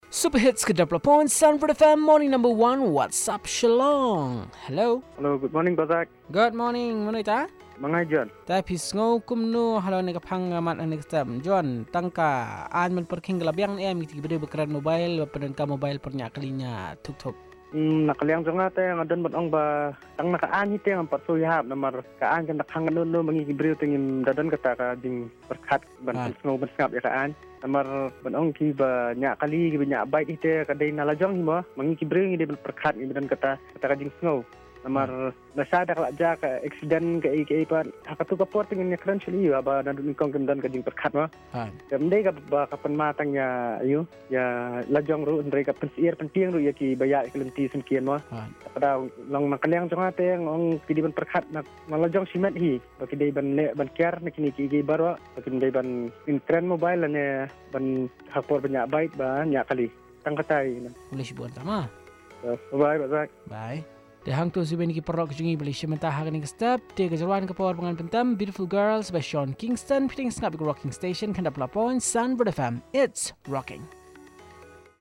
Caller 2 on using of mobile phones while driving